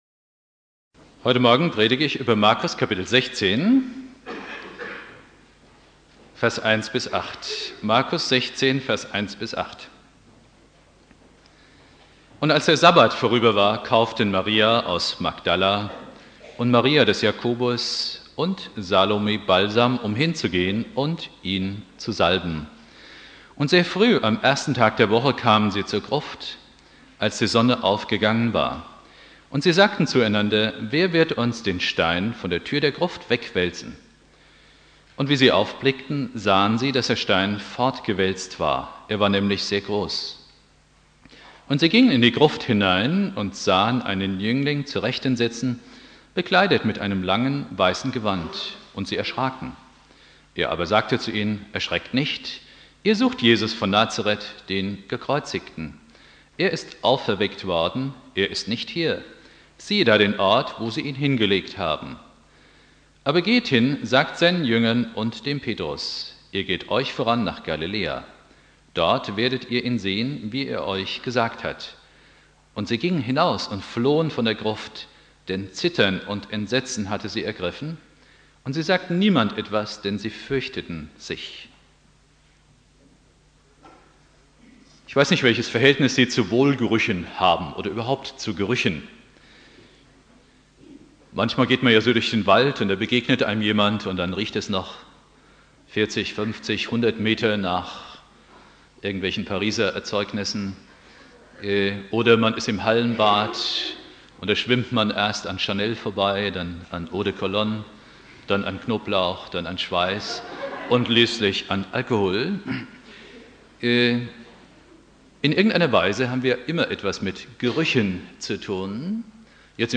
Predigt
Ostersonntag Prediger